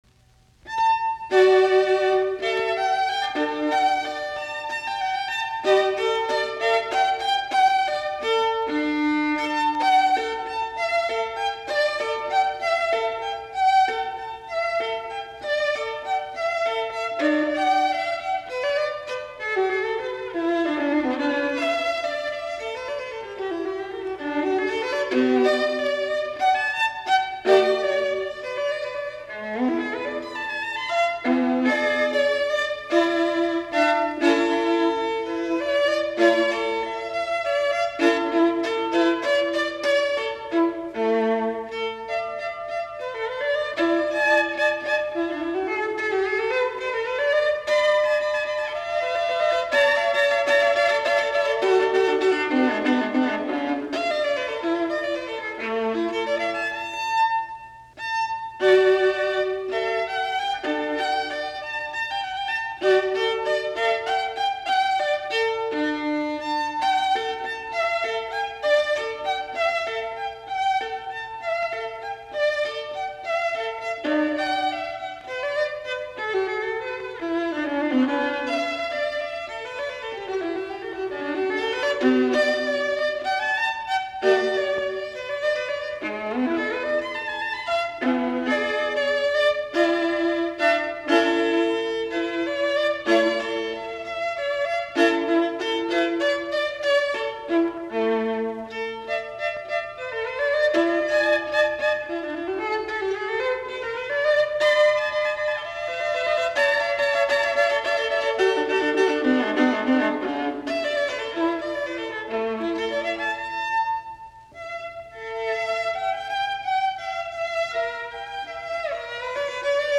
alttoviulu
Soitinnus: Alttoviulu.